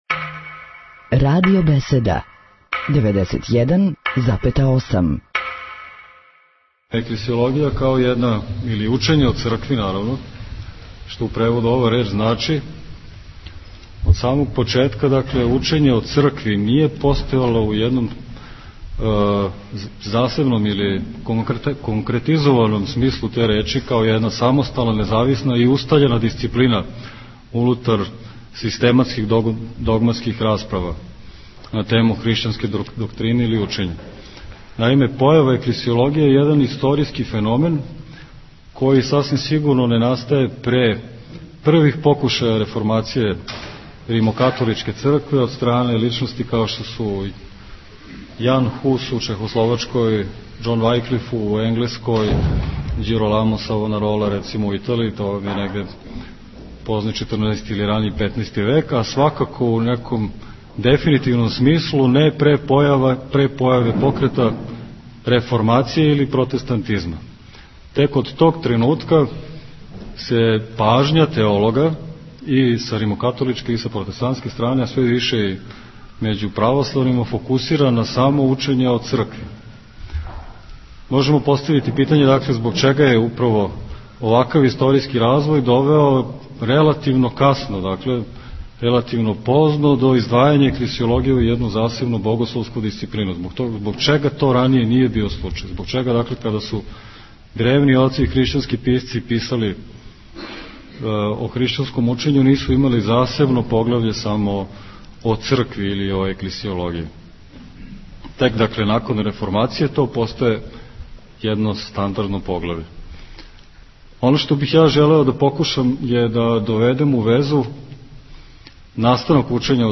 у свечаној дворани Змај-Јовине Гимназије у Новом Саду
предавање